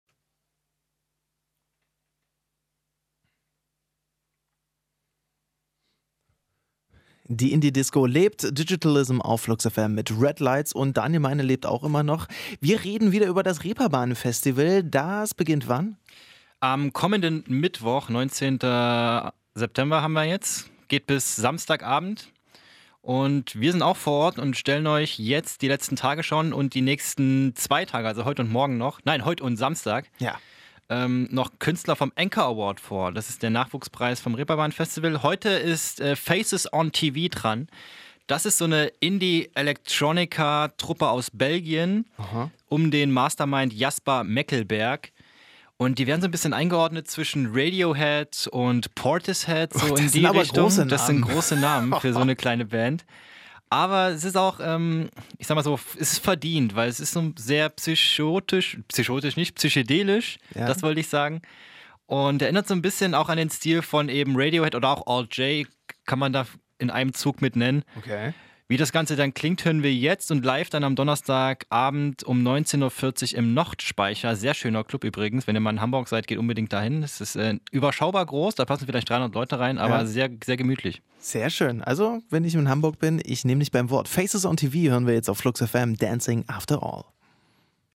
Kurzvorstellung von Faces On TV auf FluxFM